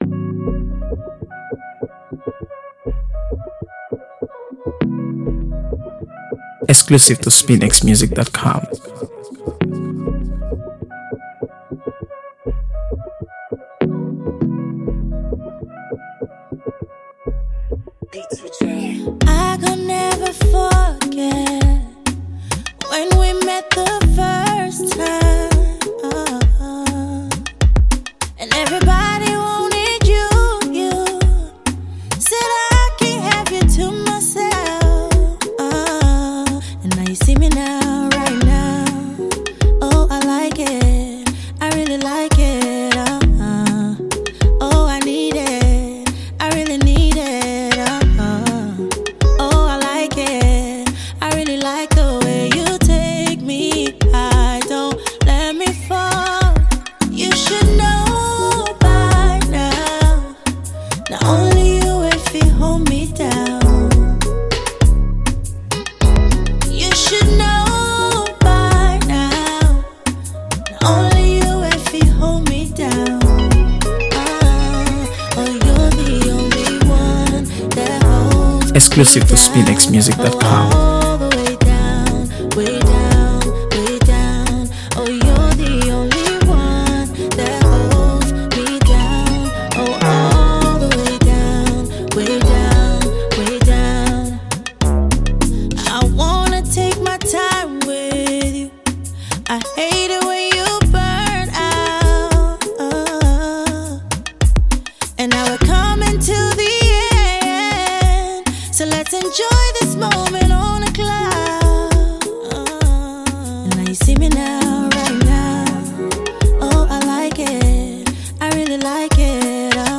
AfroBeats | AfroBeats songs
silky vocals